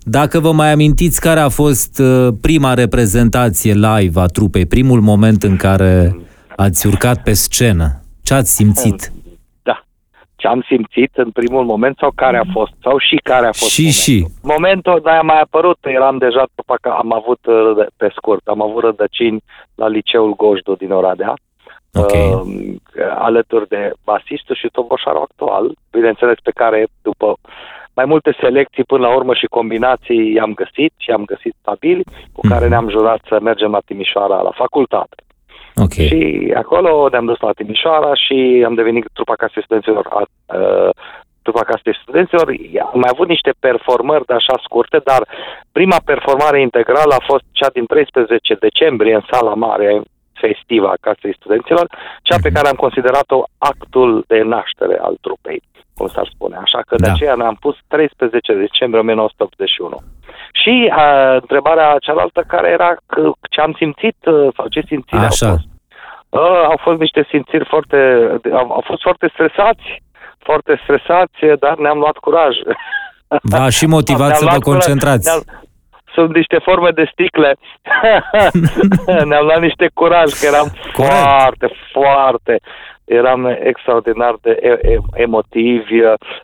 În interviul acordat EBS Radio